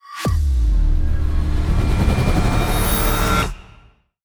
reel_anticipation1.wav